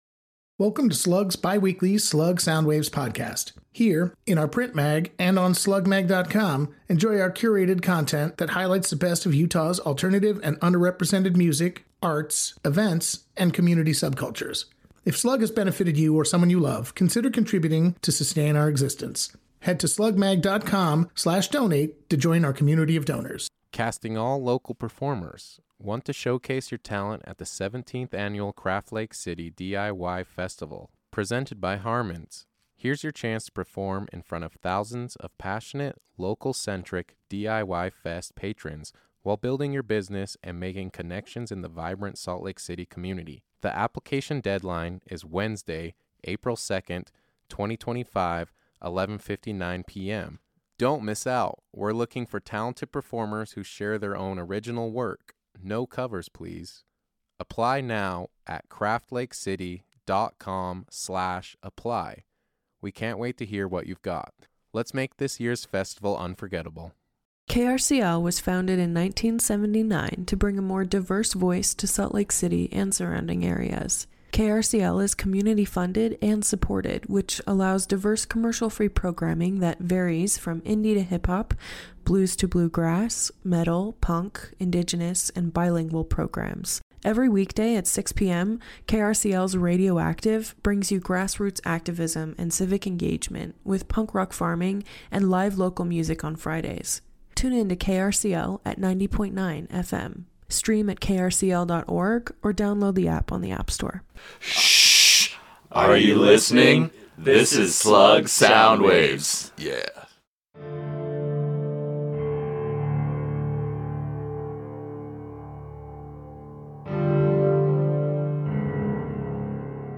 SLC post-noise outfit
vocals
bass, vocals
guitar
drums